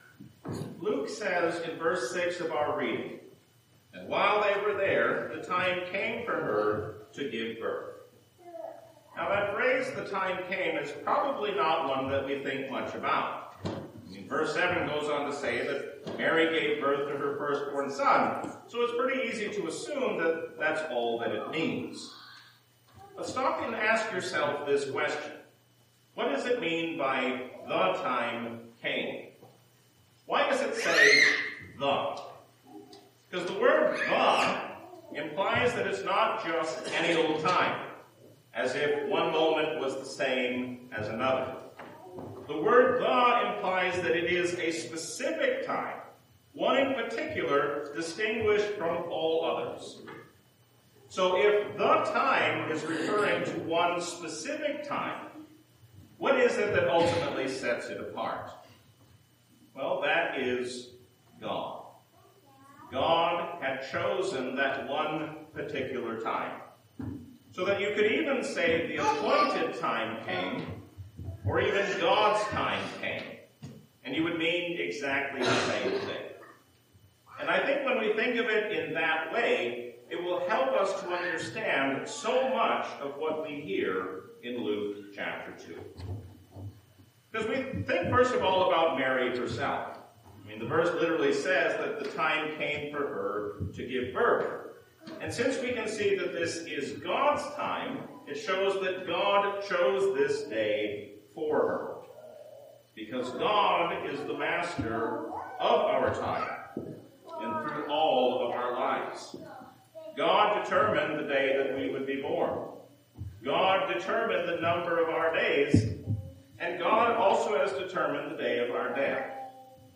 A sermon from the season "Christmas 2025." Jesus is the proof that God will keep His promises.